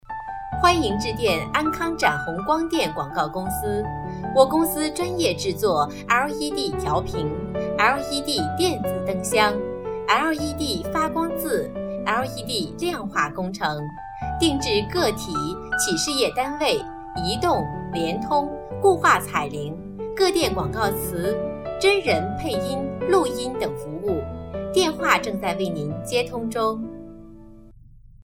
女声配音
彩铃女国42